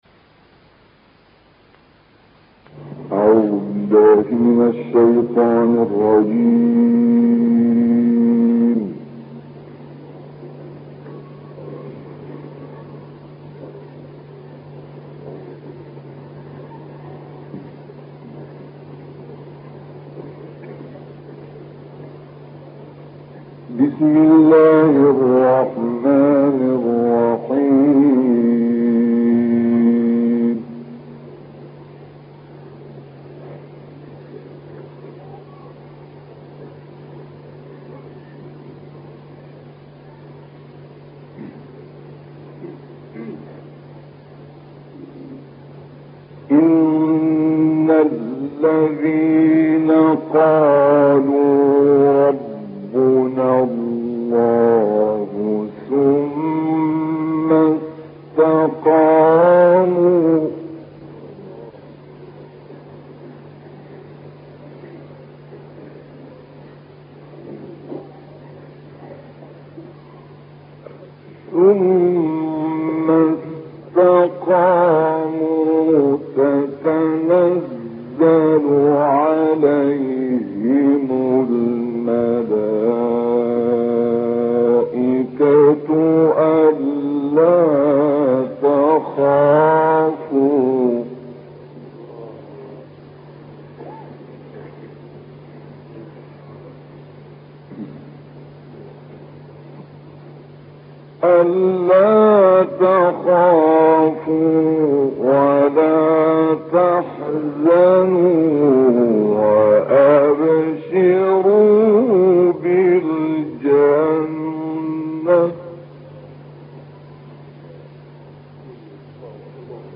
ما تيسر من سورة فصلت وقصار السور المدة ٣٨ دقيقة وقد سجل في بغداد عام ١٩٥٤